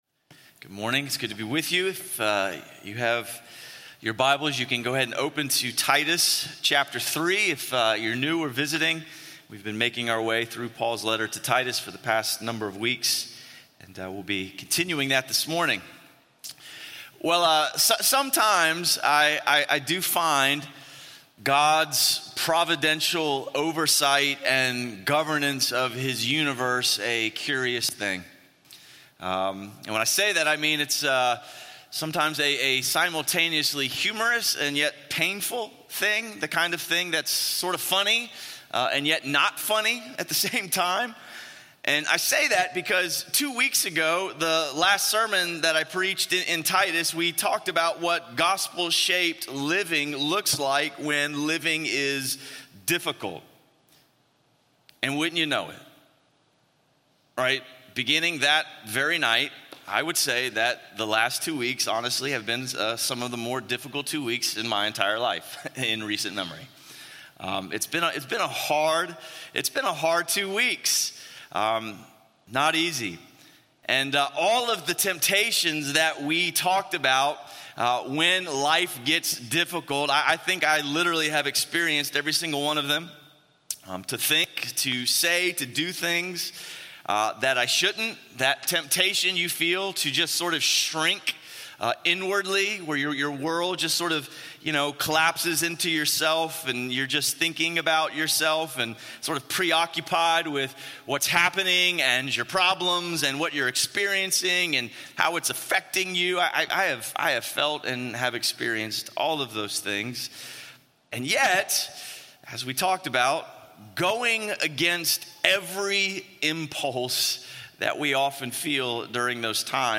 A message from the series "Good News to Good Life."